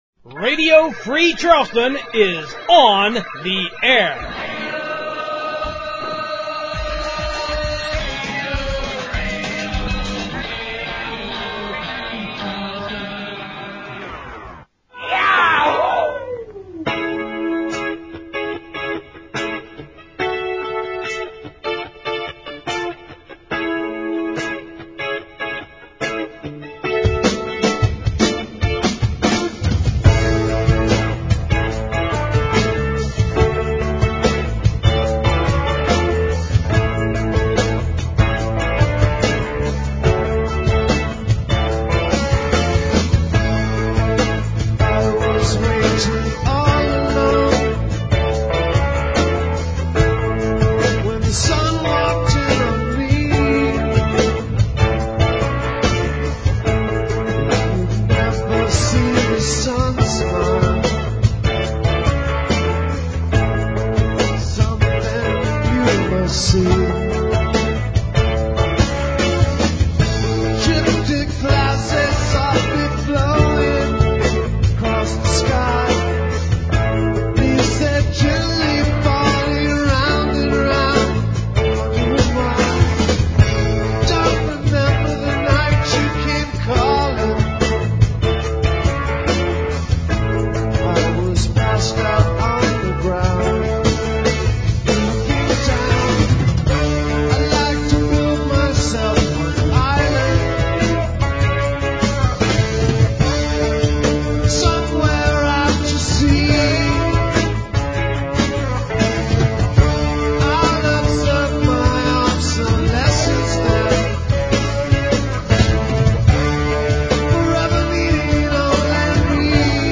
This particular show was jam-packed with great music from Charleston and the whole world.